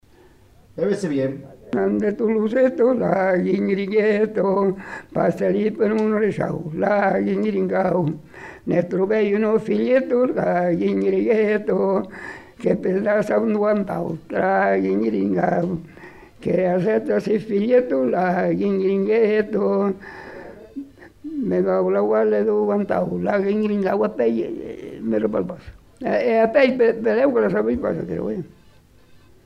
Lieu : Pompiac
Genre : chant
Type de voix : voix d'homme
Production du son : chanté
Danse : rondeau
Ecouter-voir : archives sonores en ligne